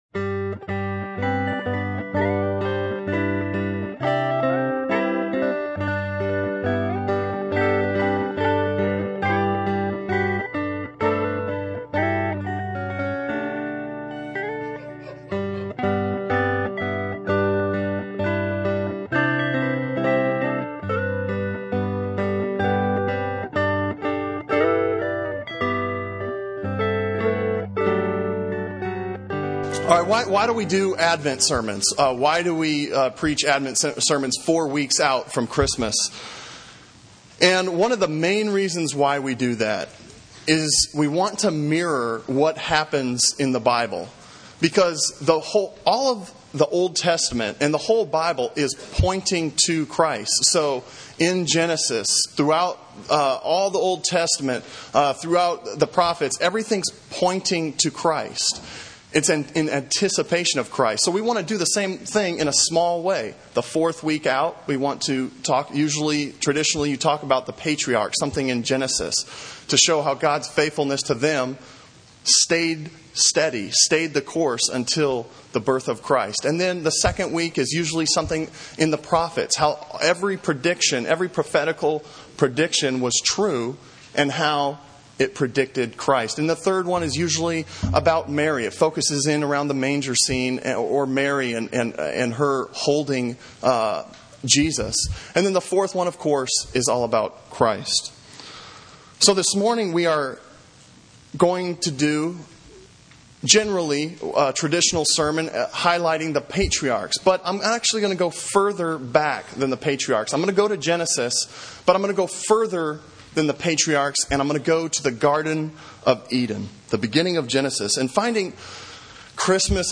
Sermon on Genesis 3:1-15 from December 3